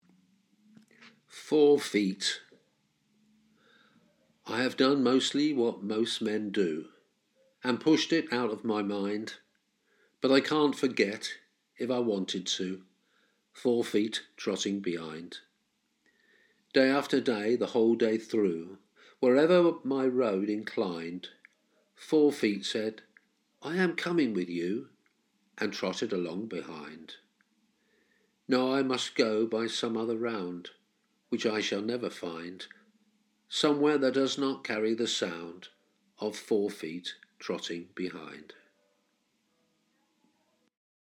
Reading Aloud